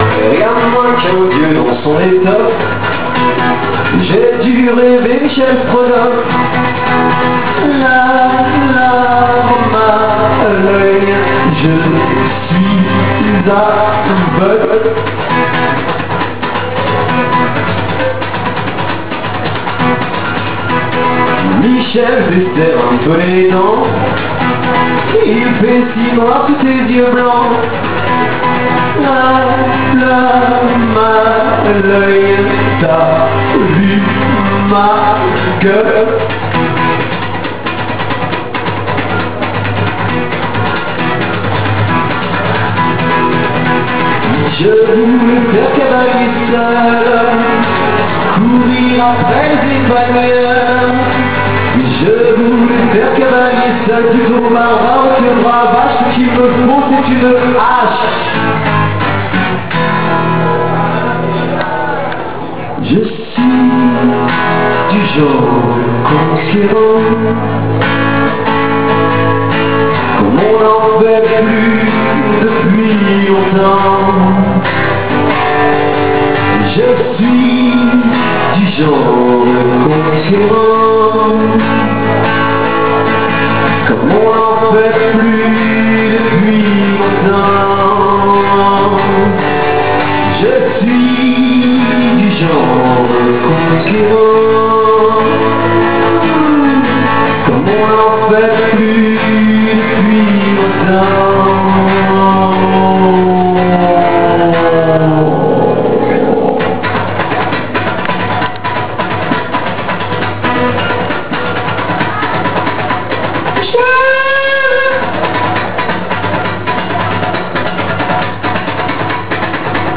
• La ViesurMars  Chansons à Textes